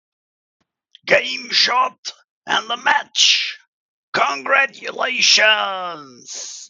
ad game shot match3 Meme Sound Effect
Category: Sports Soundboard